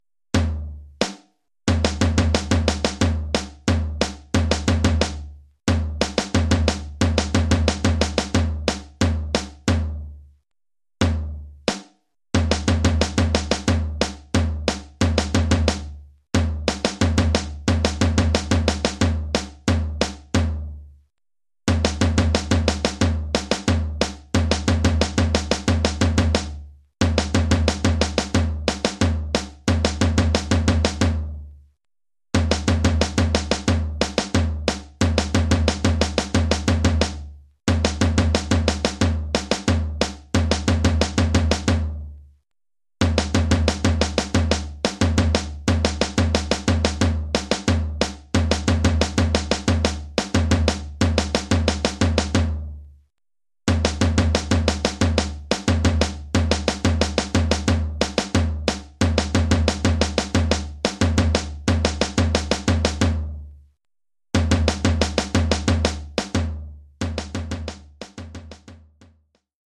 Oeuvre pour tambour seul.